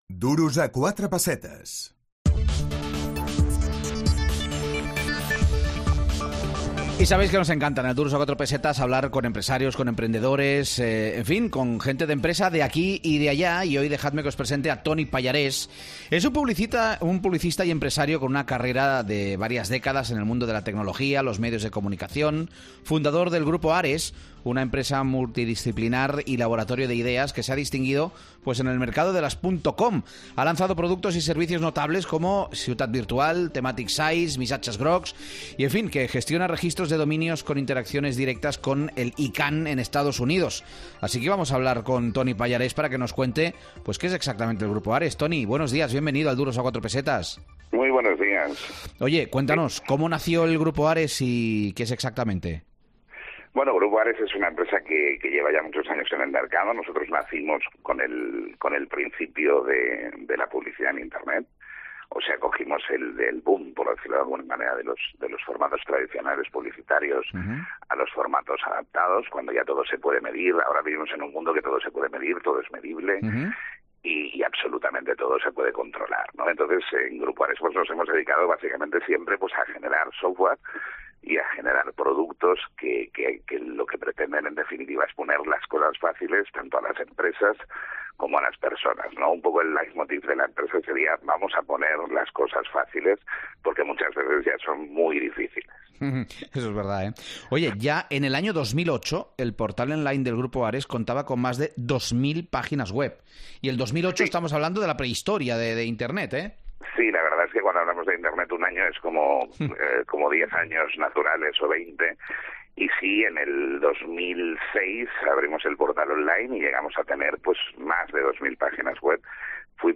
Entrevistem